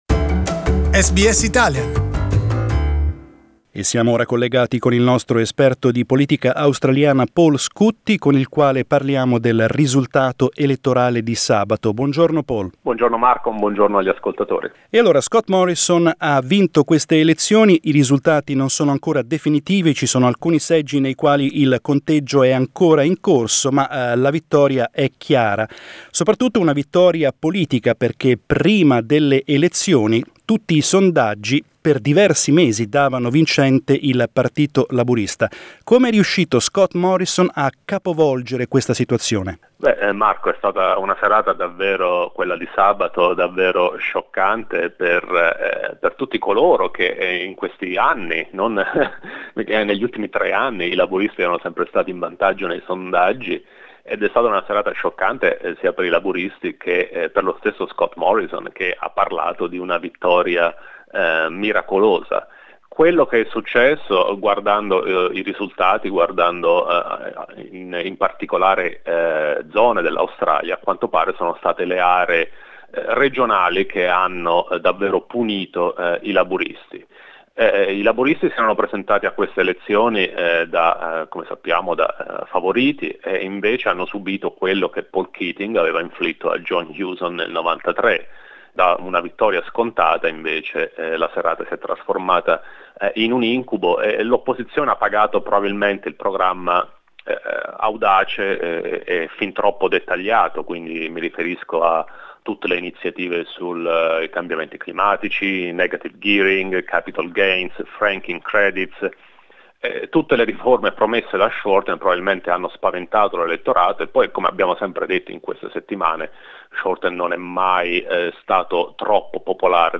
For an analysis of the outcome we spoke with Australian politics expert